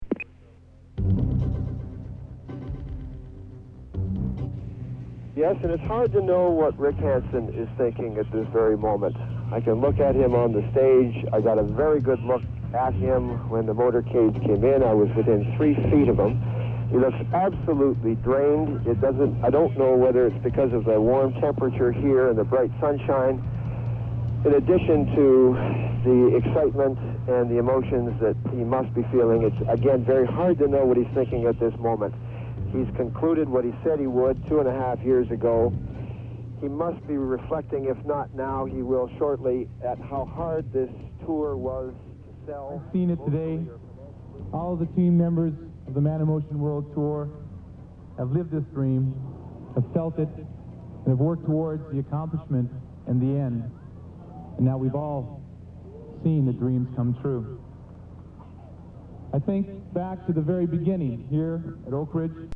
As the Rick Hansen 25 year anniversary relay wraps today up where it began in Vancouver, I’m happy to reach into the digital archives to relive his finish line moment.